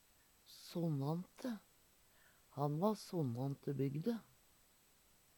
Mange si sønnante i dag, men dei eldre si sonnante.